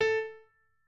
pianoadrib1_19.ogg